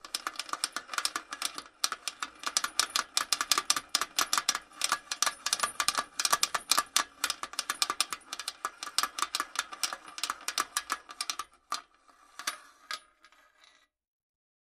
Chains
Chain, Small, Towing On A Metal